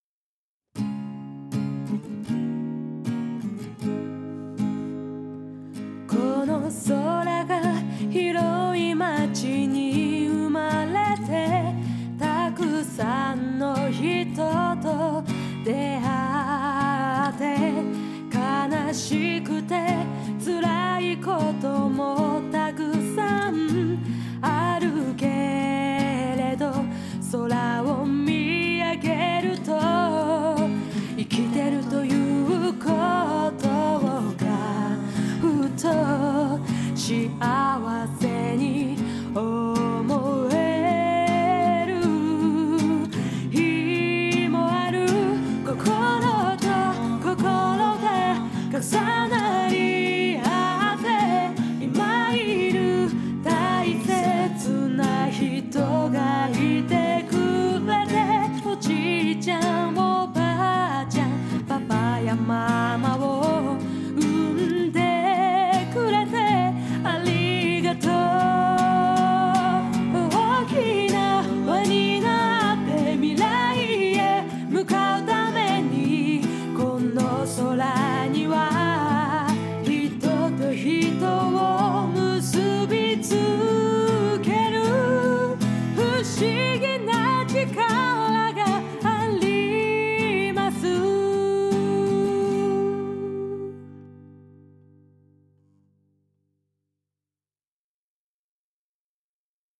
の録音を１０日、中野坂上のスタジオで行った。
というメトロノームのようにリズムを刻む音と、事前に録音したギターの伴奏が
最後は、今日、録音したナレーションと音楽をミックスしてすべてを終えた。